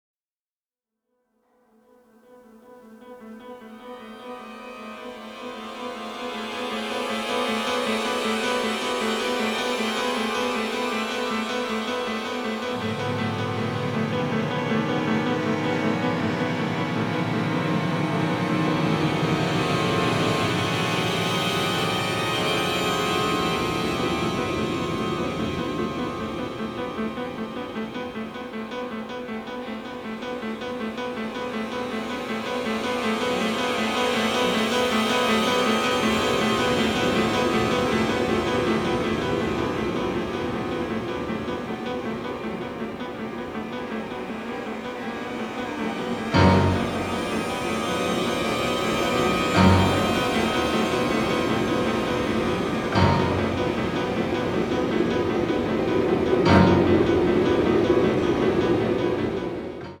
giallo score
sophisticated avant-garde sound